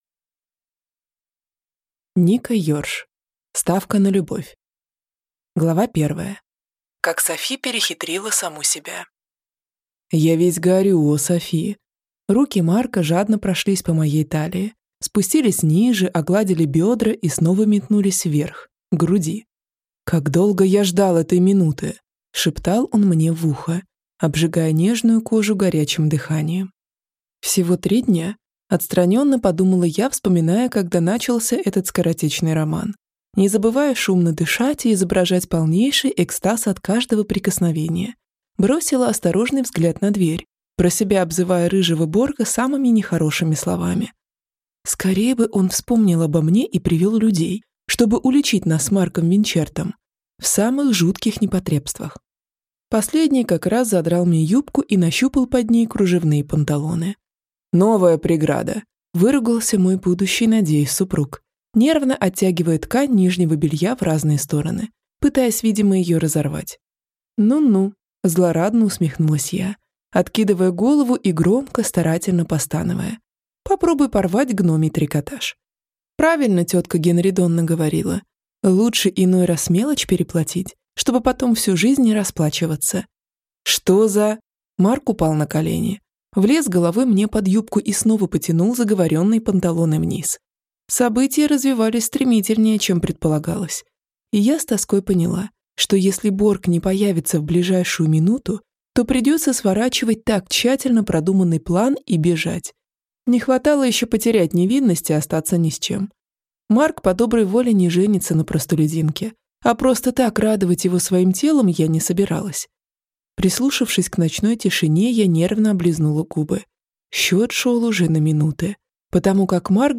Аудиокнига Ставка на любовь - купить, скачать и слушать онлайн | КнигоПоиск
Прослушать фрагмент аудиокниги Ставка на любовь Ника Ёрш Произведений: 8 Скачать бесплатно книгу Скачать в MP3 Вы скачиваете фрагмент книги, предоставленный издательством